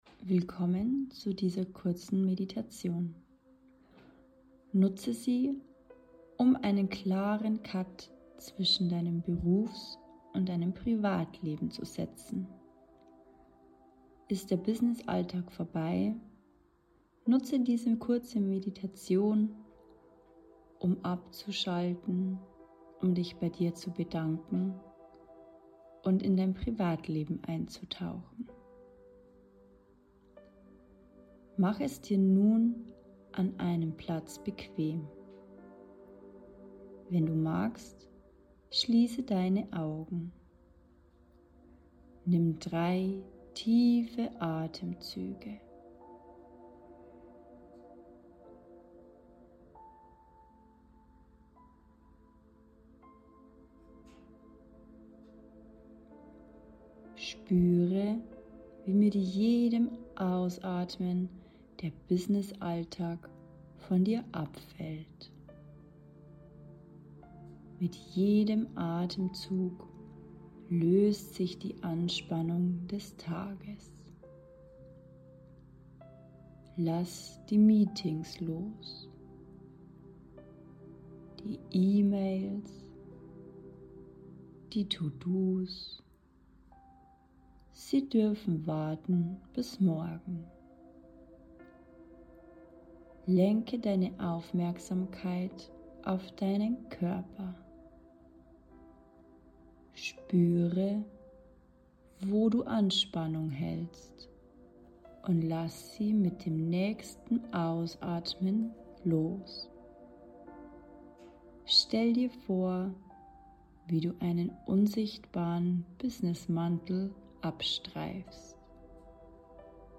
5-Minuten-Meditation